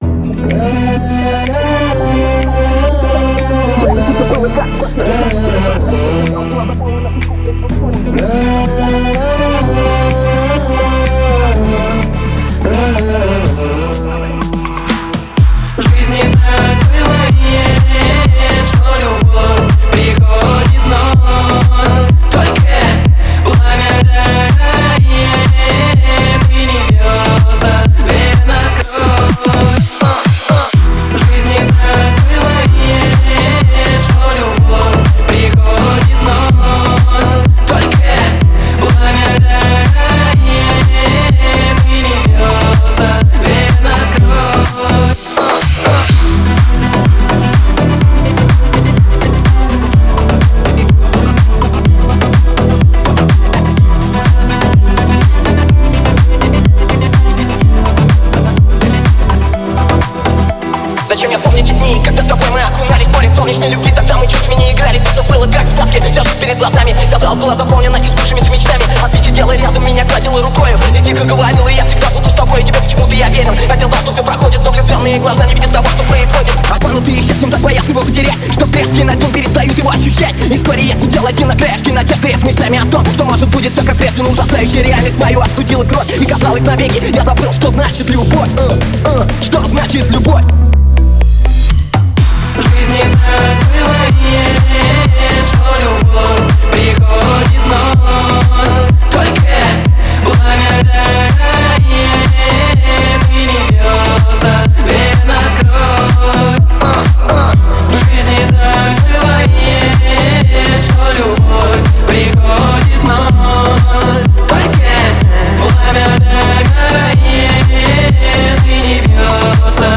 Лаунж